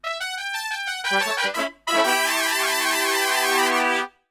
FUNK3 E M.wav